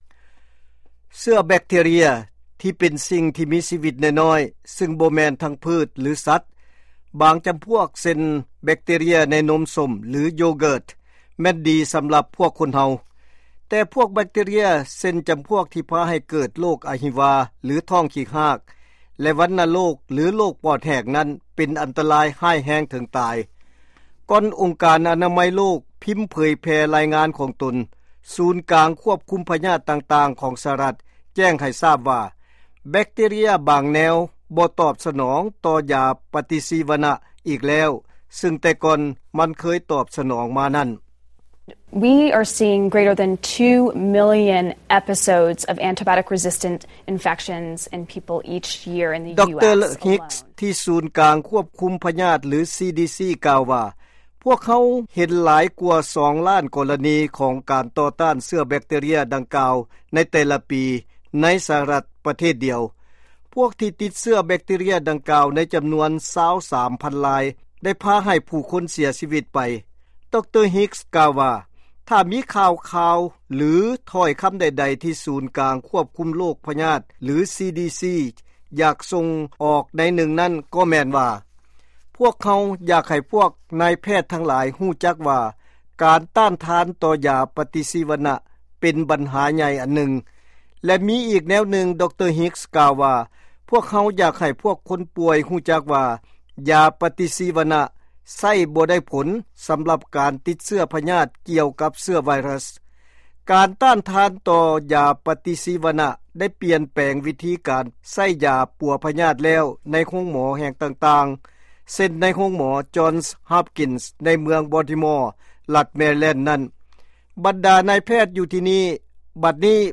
ເຊີນຟັງສາລະຄະດີ ກ່ຽວກັບການຕ້ານທານຕໍ່ຢາປະຕິຊີວະນະ